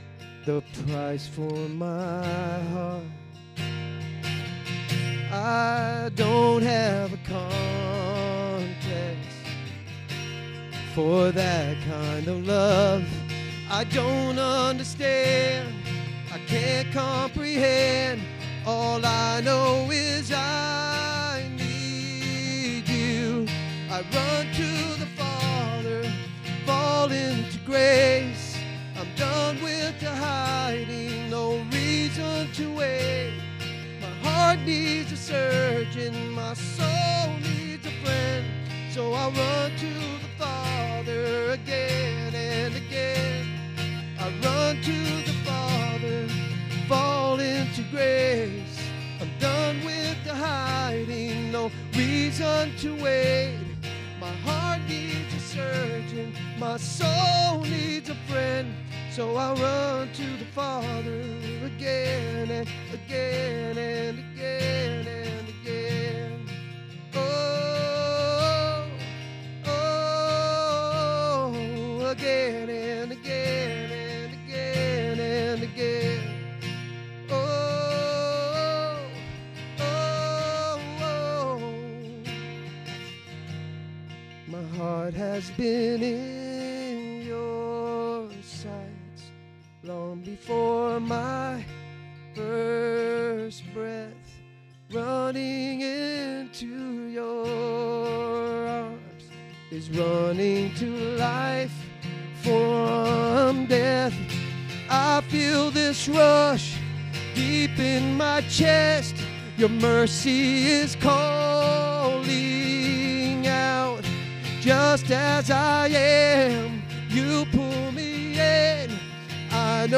SERMON DESCRIPTION When I was a kid I was always asked, what is your favorite.